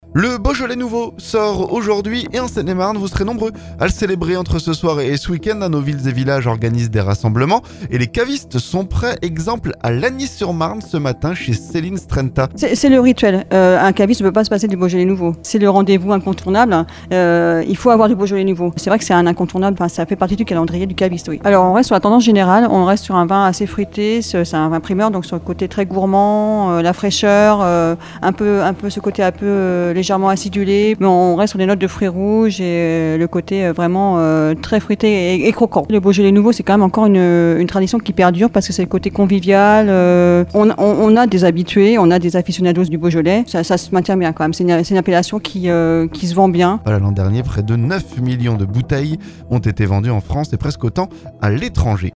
BEAUJOLAIS NOUVEAU - Chez un caviste de Seine-et-Marne